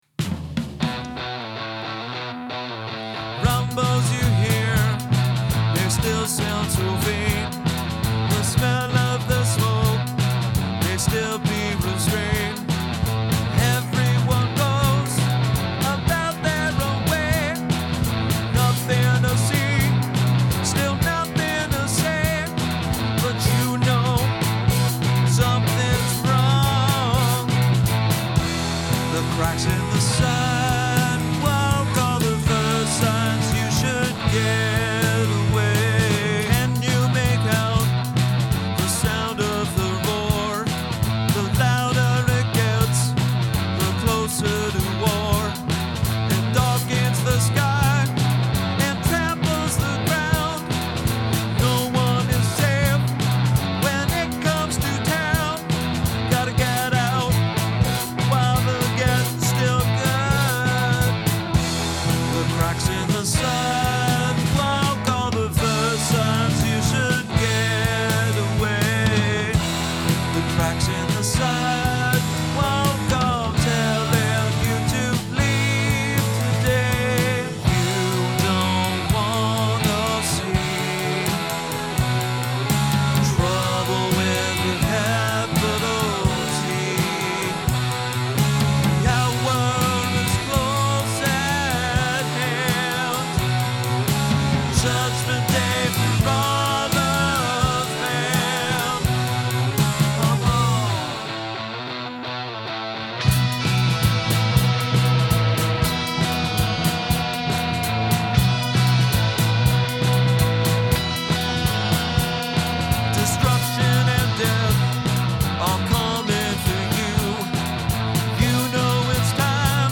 Guitar
Bass
Drums, Vocals, Lead Guitar and production